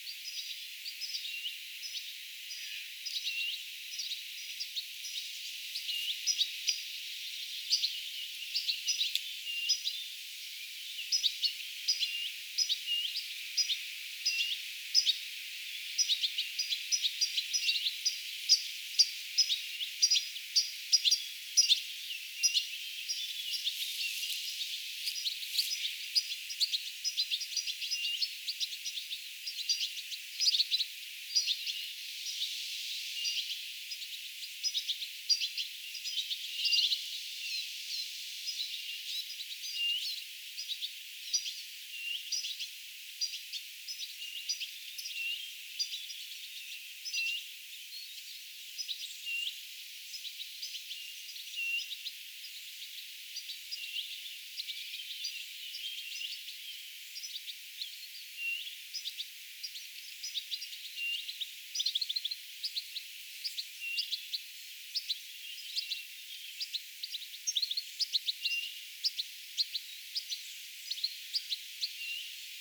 Tänään näkyi ja kuului myöskin oletettu
tiliparvesta kuuluvaa ääntelyä
Ehkäpä suurin osa äänistä kuuluu nuorille linnuille.
tikliparvesta_kuulunutta_aantelya_olisiko_nuorten_aania_eniten.mp3